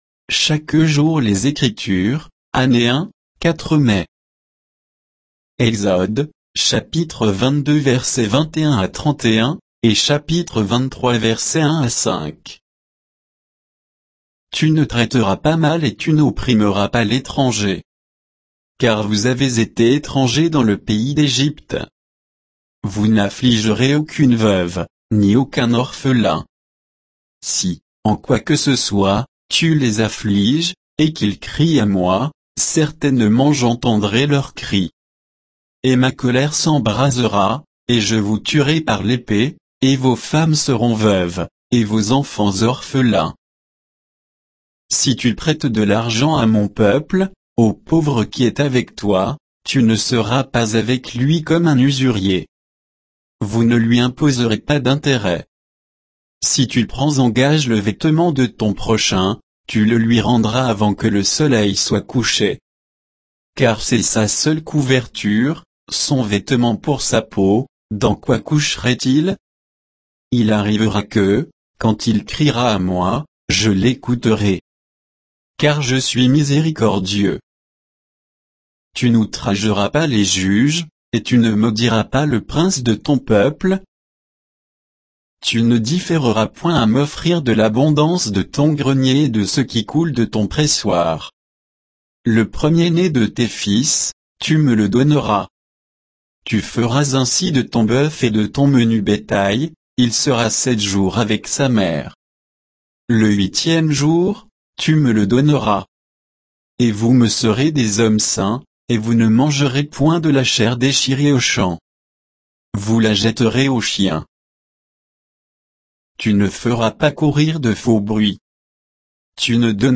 Méditation quoditienne de Chaque jour les Écritures sur Exode 22